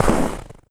STEPS Snow, Run 19.wav